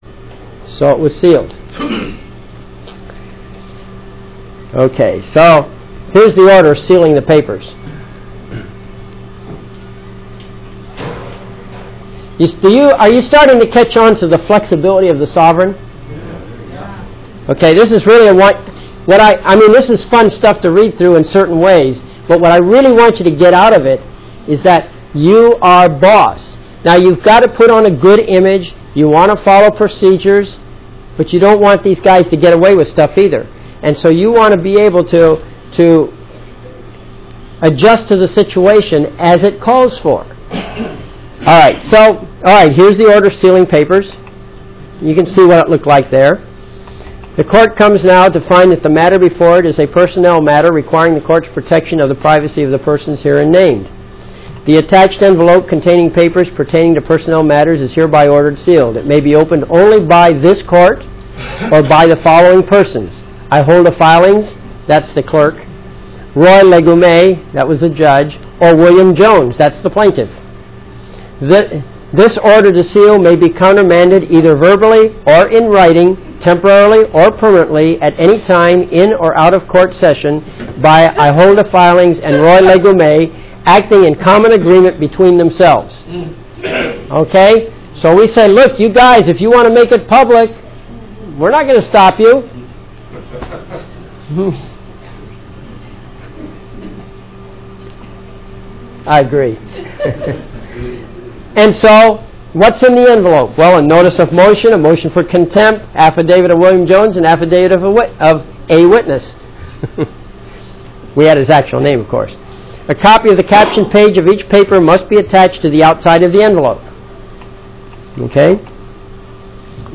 SOVEREIGNTY AUDIO LECTURE
This audio extract from the video seminar is a general discussion mostly about SOVEREIGNTY and related subjects.